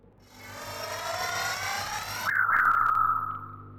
Psychic animal pulser being worked on by a pawn and the subsequent activation sound